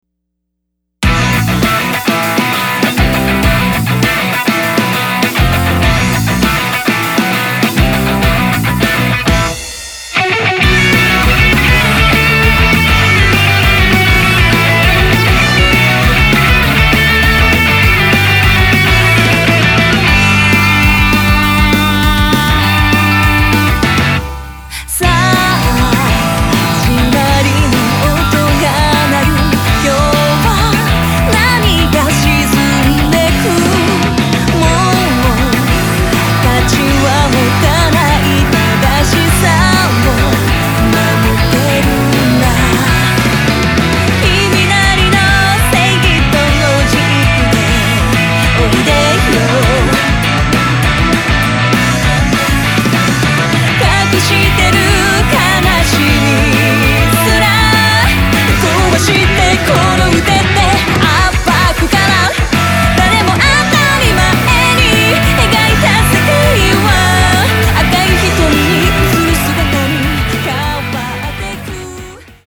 儚さポップ＆ロック全開！
Guitar
Bass
Piano & strings